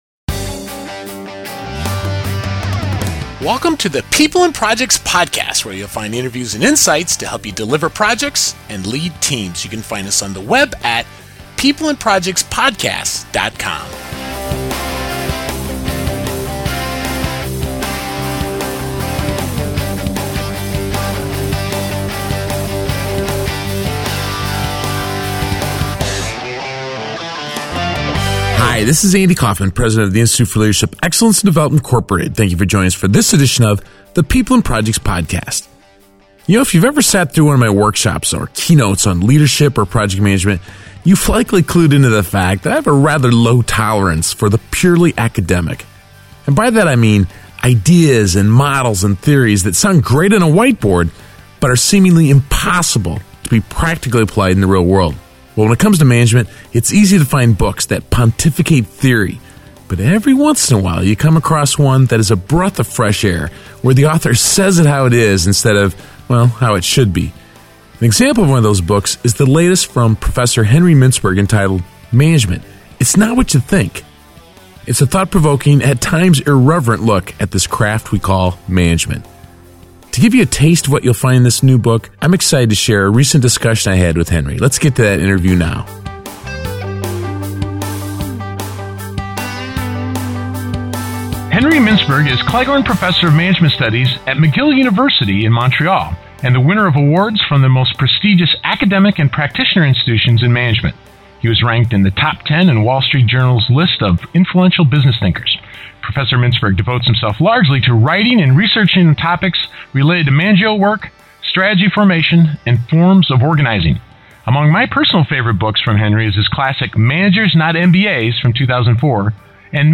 PPP 047 | Management: It’s Not What You Think! An interview with Henry Mintzberg – People and Projects Podcast